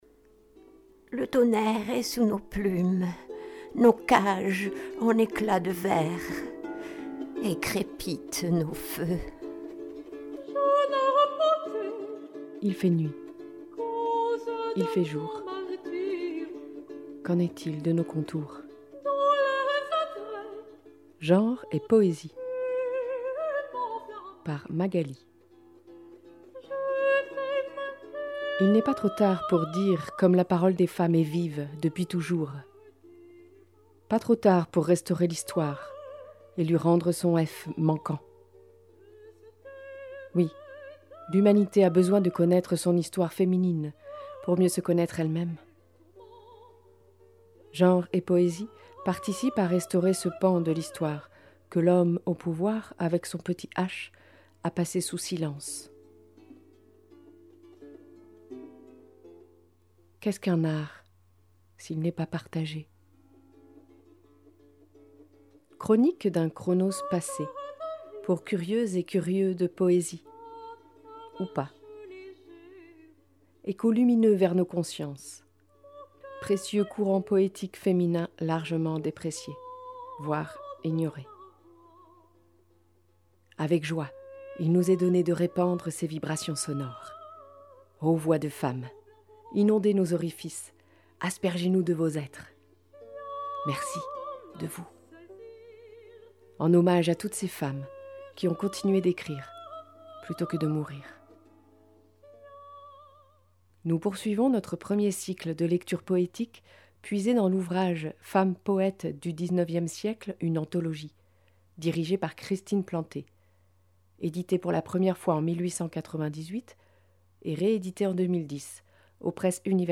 emissions